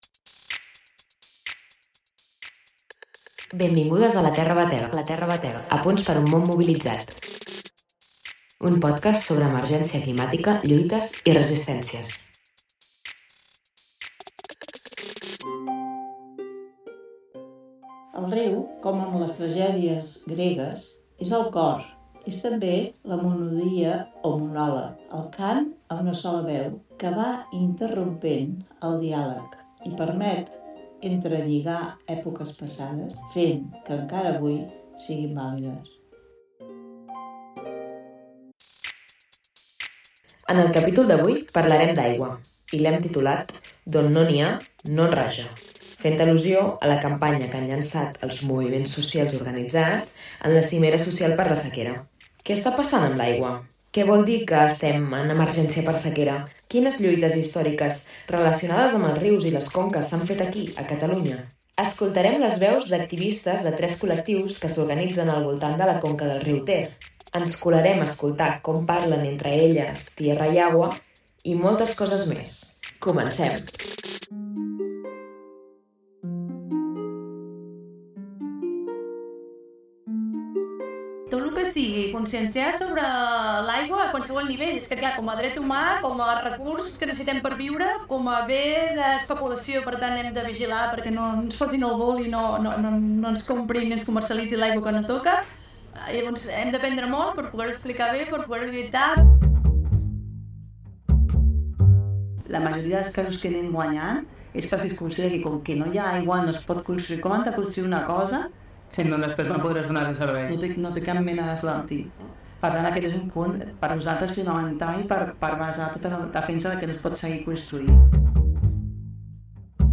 Escoltarem les veus d'activistes de tres col·lectius que s’organitzen al voltant de la conca del riu Ter: el Grup de Defensa del Ter, Aigua és Vida Girona i SOS Costa Brava, ens colarem a escoltar com parlen entre elles tierra y agua, i moltes coses més!